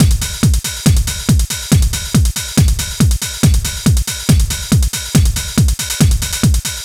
NRG 4 On The Floor 020.wav